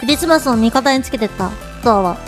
Worms speechbanks
Drop.wav